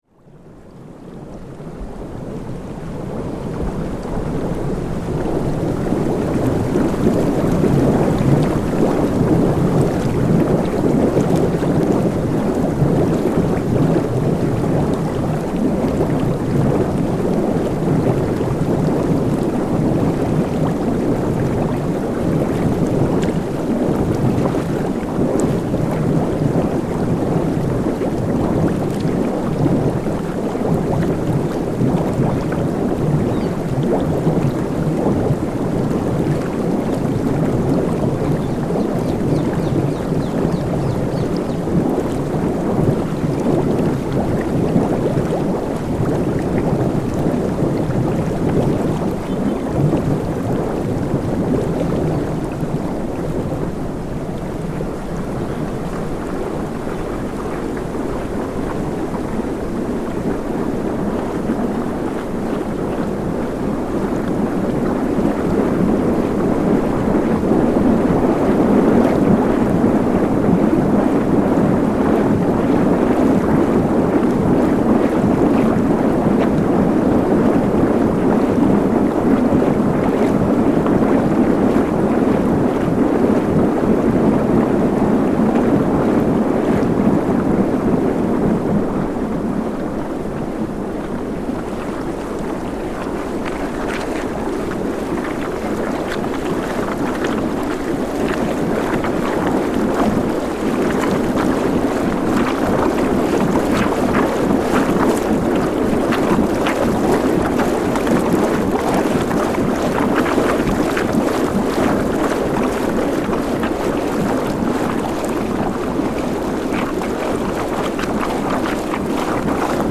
An extract from a soundwalk around this water feature in Hyde Park, London. It’s an oval granite channel measuring between 6 and 10 meters wide, and about 50 by 80 metres in diameter.
Water flows across a varied surface cut into the granite, creating calm ripple sounds, fast torrents, turbulent bubbling…